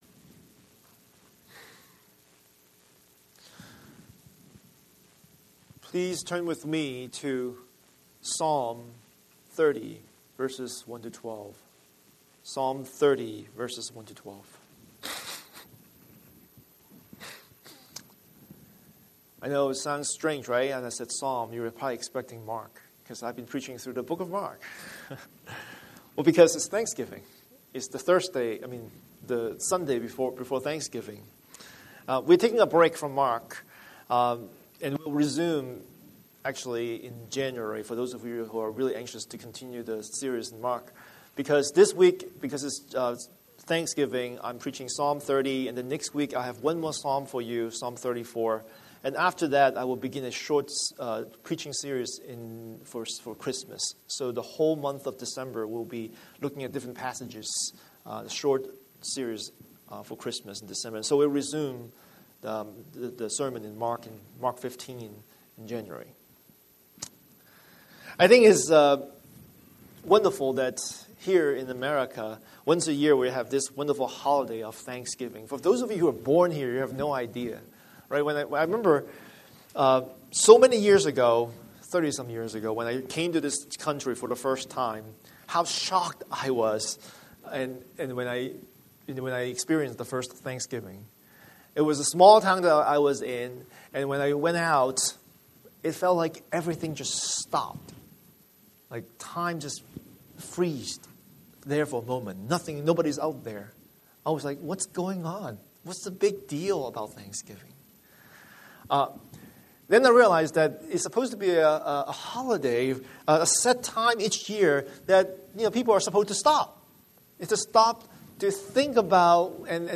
Scripture: Psalm 30:1–12 Series: Sunday Sermon